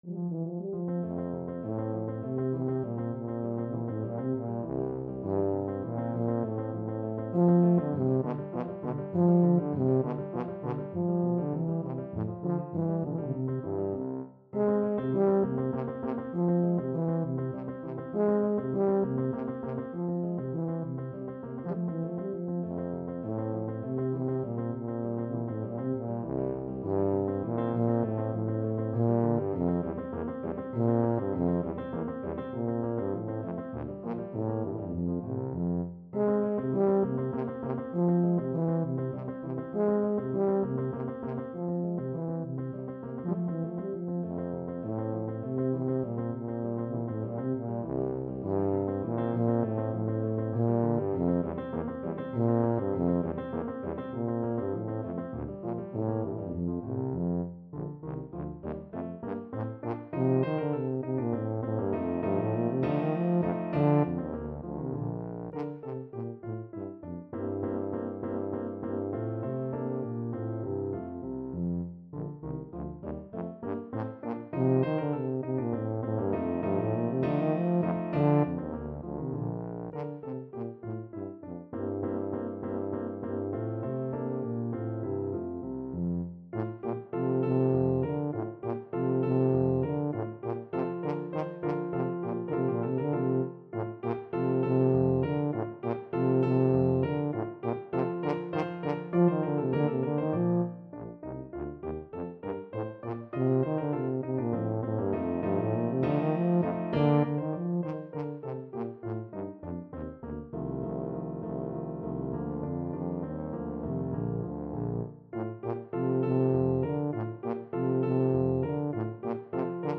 F major (Sounding Pitch) (View more F major Music for Tuba )
3/4 (View more 3/4 Music)
Moderato
Classical (View more Classical Tuba Music)